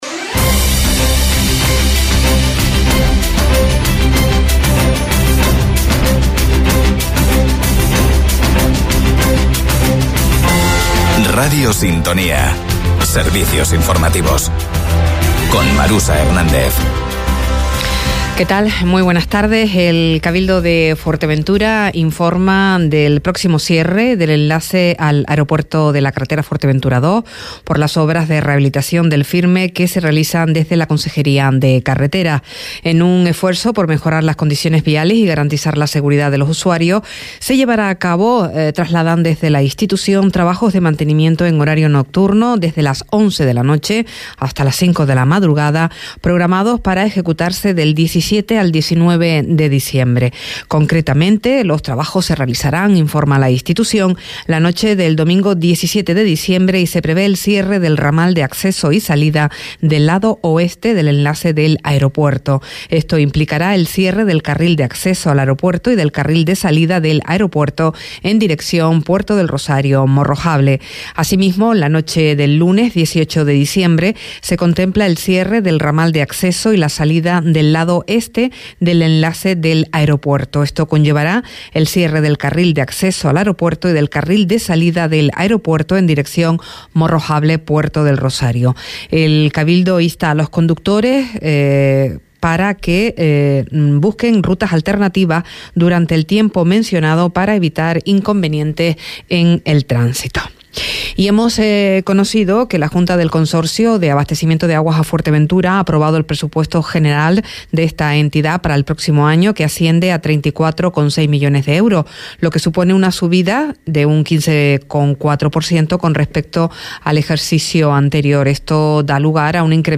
Servicios Informativos